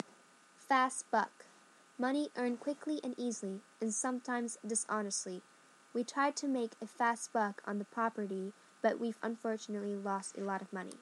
英語ネイティブによる発音は下記のリンクから聞くことができます。